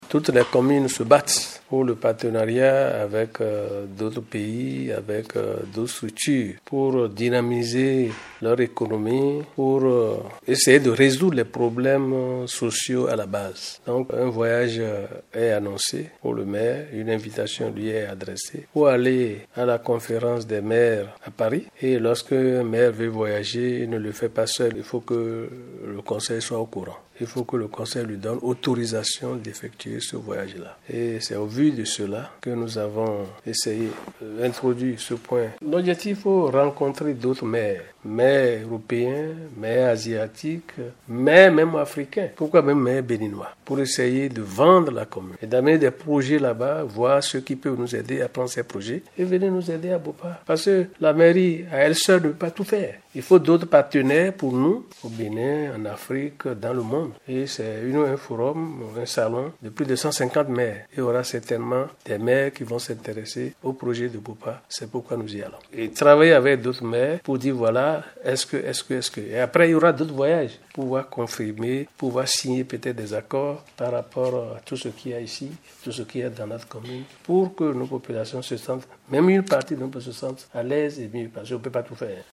Les travaux de la dite session se sont déroulées dans la grande salle de délibération de l’Hôtel de ville.
Le maire Abel Djossou évoque les objectifs de ce voyage et les retombées pour la commune de Bopa.